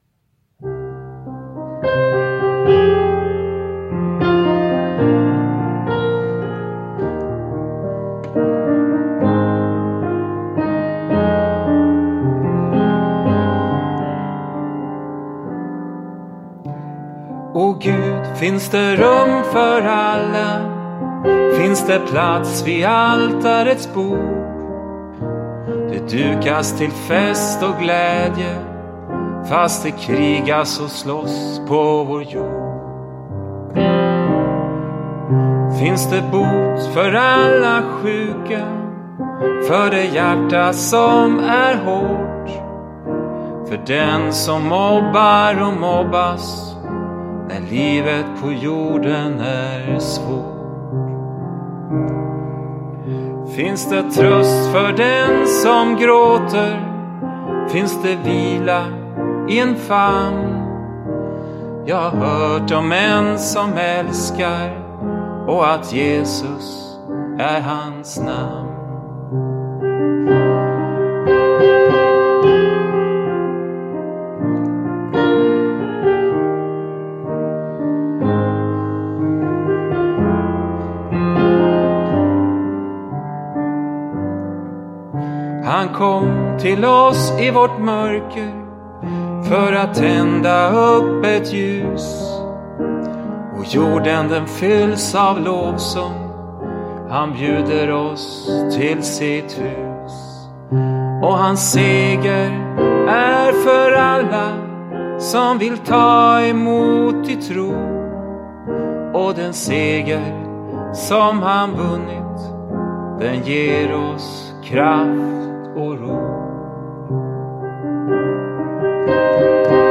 sång, gitarr, piano, munspel
piano och bas
rytmer
gitarr